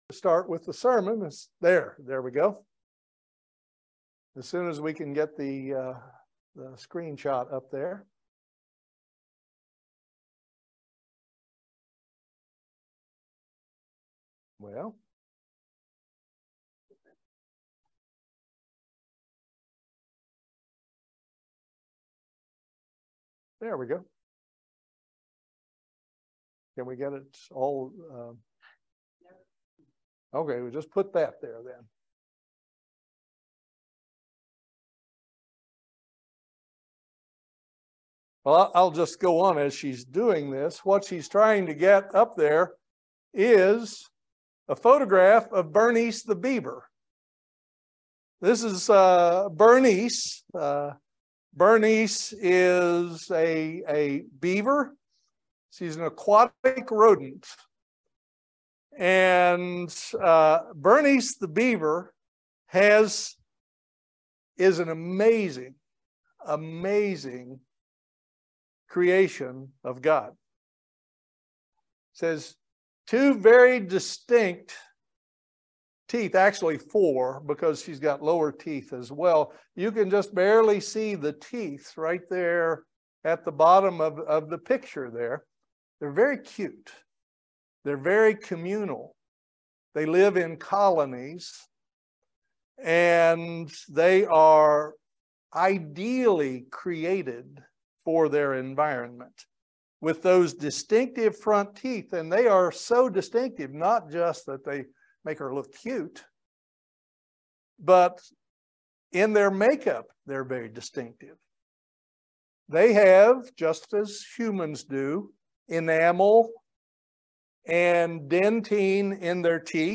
This Sermon gives you ideas on how to be a more thankful person in a society where everything is about "Self"
Given in Lexington, KY